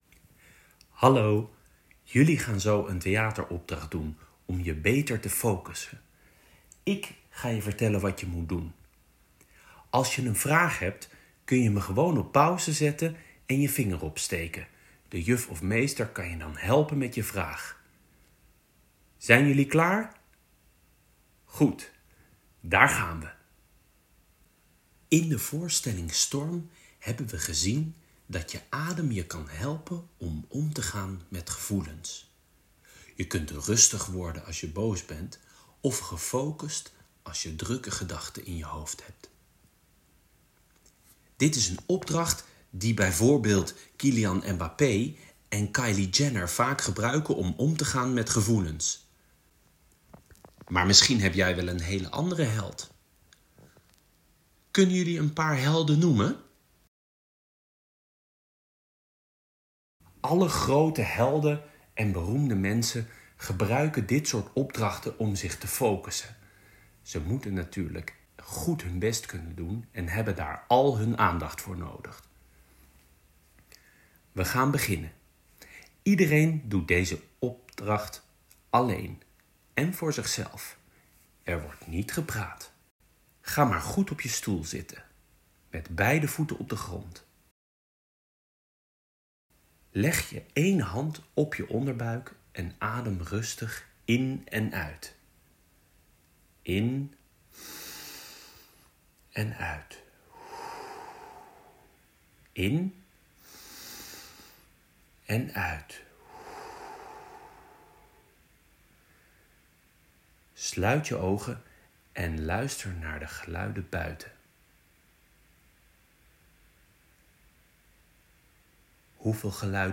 STORM-_-meditatie-opdracht-final2.m4a